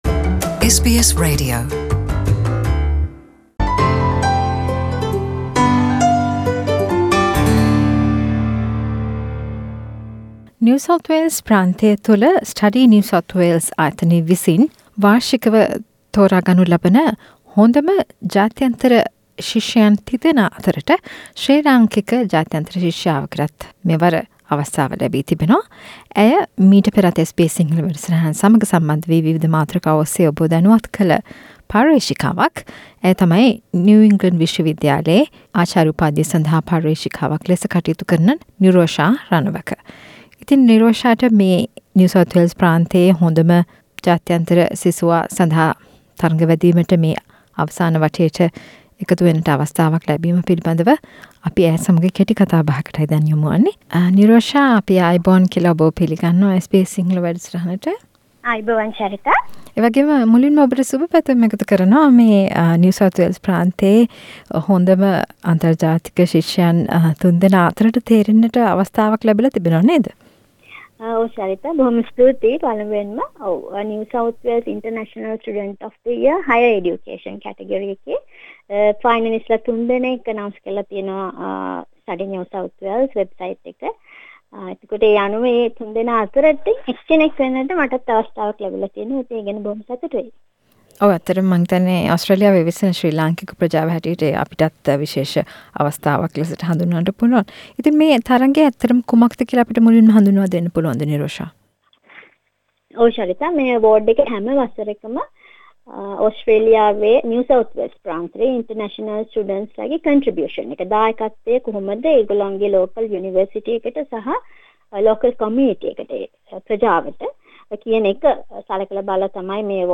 කතා බහක්